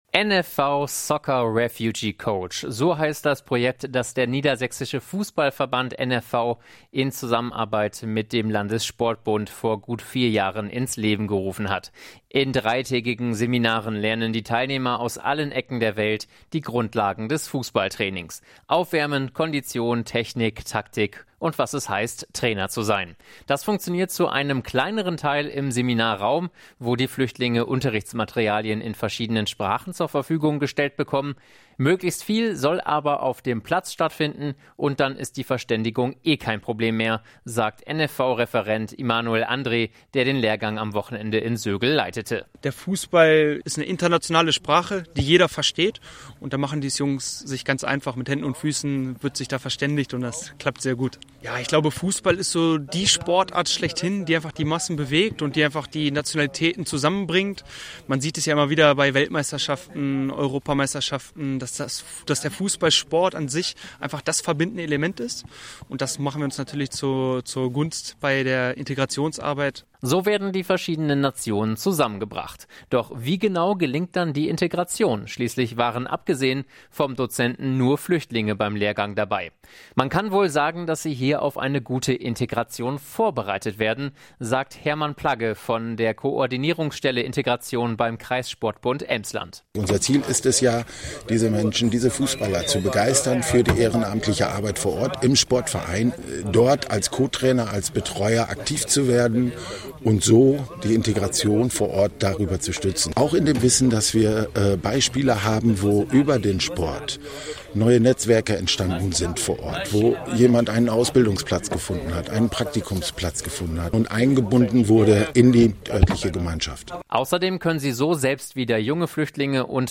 An der Sportschule Emsland in Sögel haben sie an einem Trainer-Lehrgang teilgenommen. Reporter